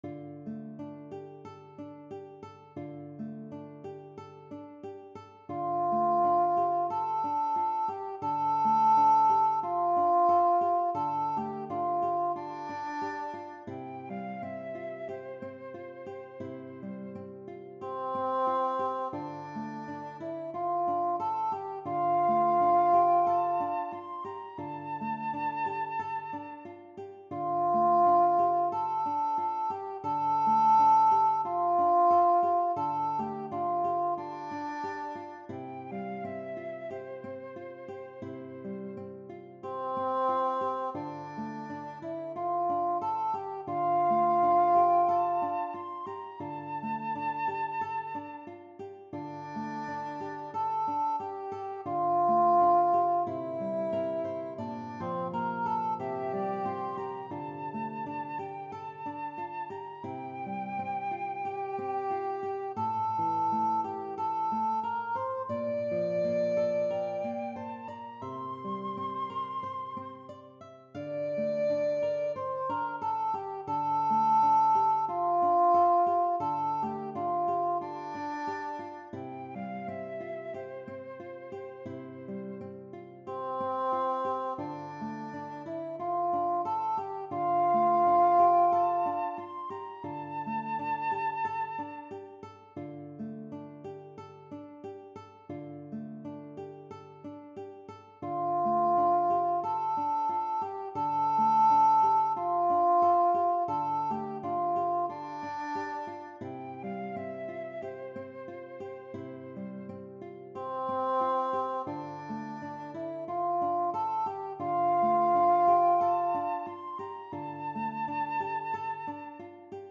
Voicing/Instrumentation: Choir Unison
Flute Piano